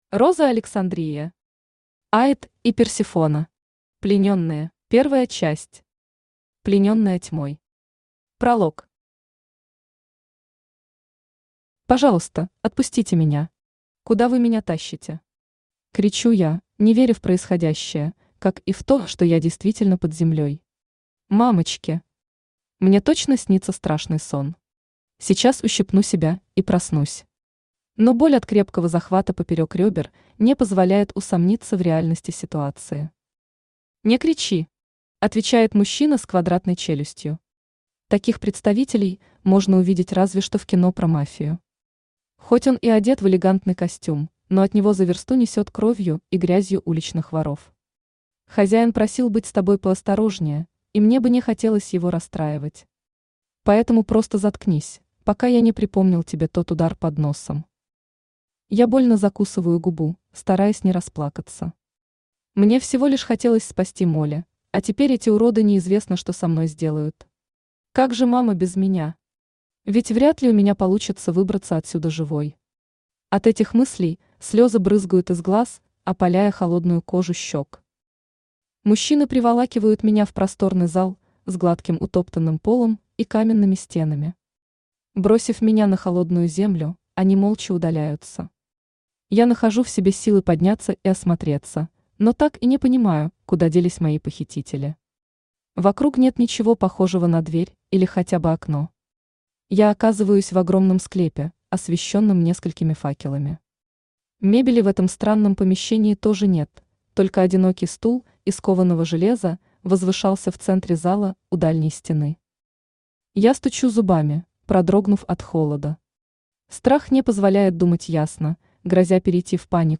Аудиокнига Аид и Персефона. Плененные | Библиотека аудиокниг
Плененные Автор Роза Александрия Читает аудиокнигу Авточтец ЛитРес.